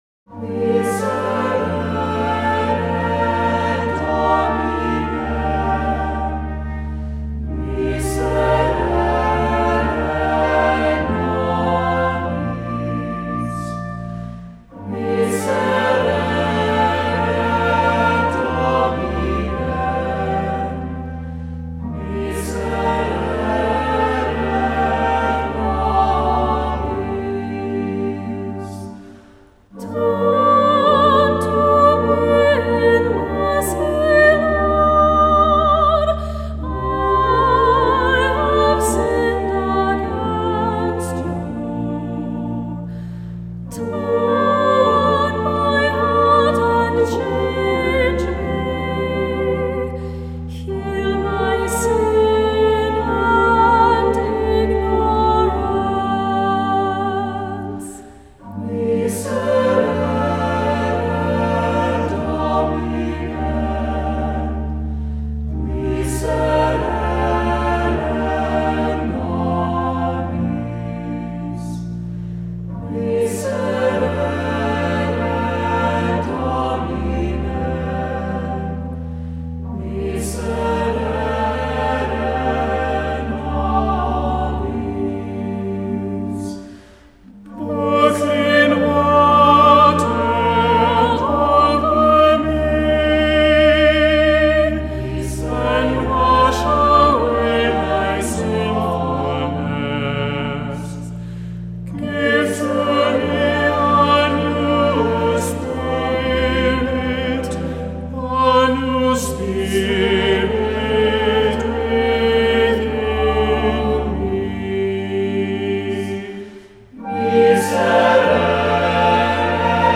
Voicing: Cantor,Assembly,SATB